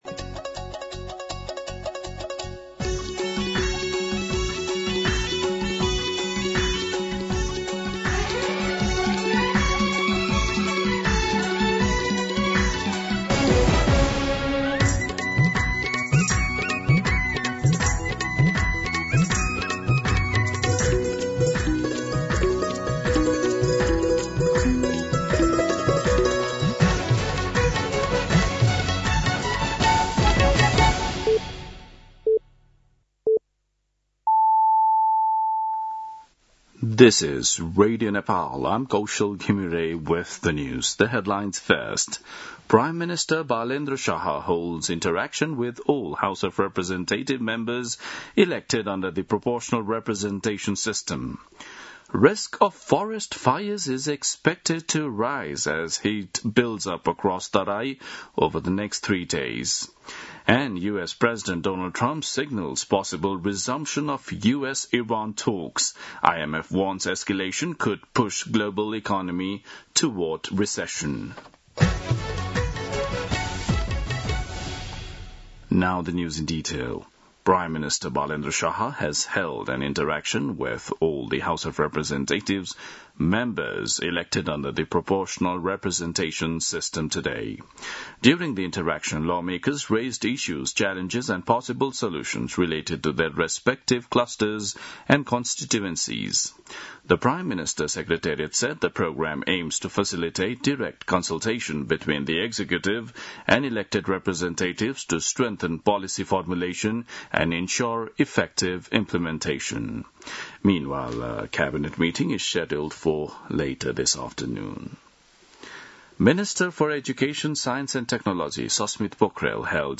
An online outlet of Nepal's national radio broadcaster
दिउँसो २ बजेको अङ्ग्रेजी समाचार : २ वैशाख , २०८३